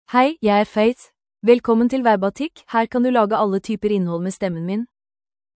Faith — Female Norwegian Bokmål AI voice
Faith is a female AI voice for Norwegian Bokmål (Norway).
Voice sample
Listen to Faith's female Norwegian Bokmål voice.
Female